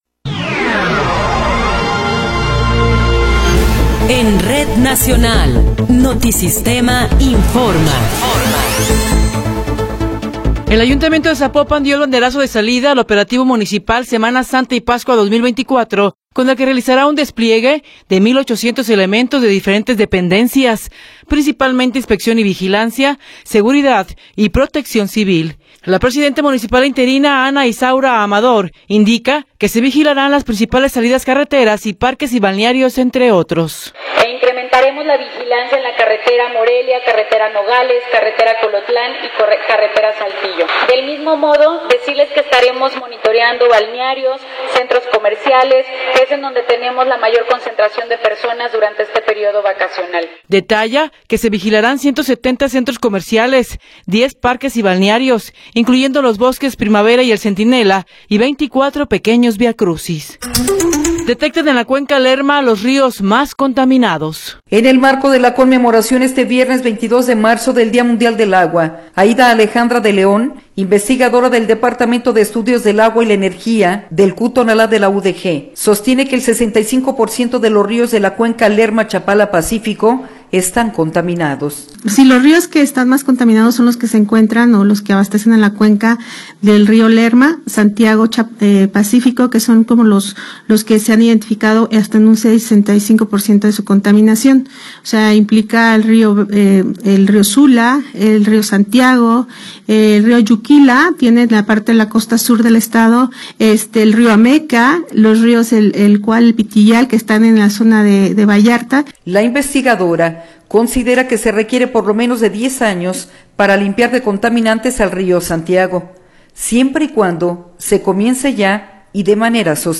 Noticiero 11 hrs. – 22 de Marzo de 2024